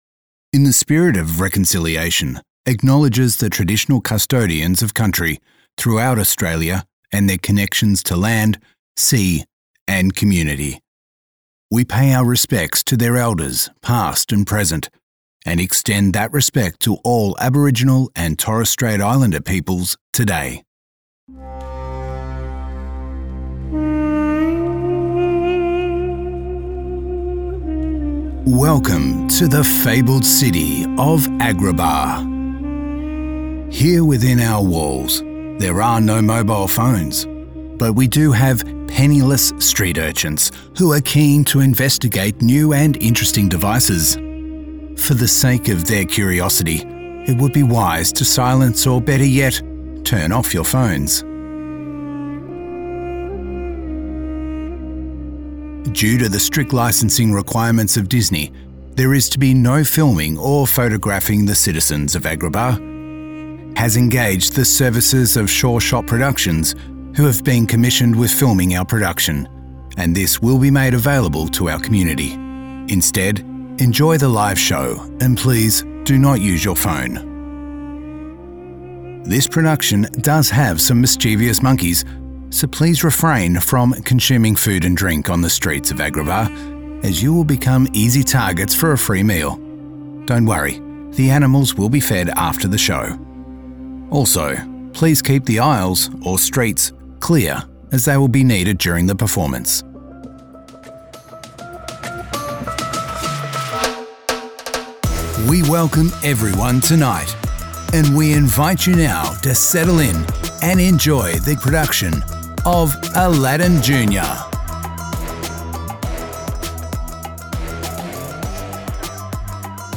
Male
English (Australian)
Casual, professional or announcer-ry, he brings every script to life.
0318Preshow.mp3